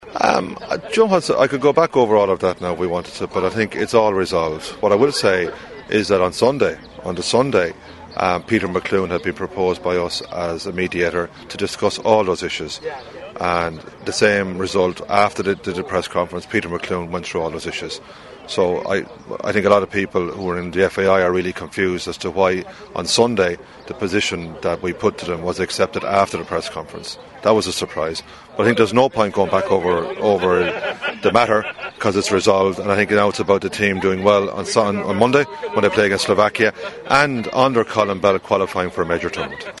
Delaney spoke to the Big Red Bench at the official opening of Ringmahon Rangers' new dressing rooms on Saturday, and when asked about the players holding a press conference last Wednesday about their grievences and their request for certain conditions to be met, he had this to say: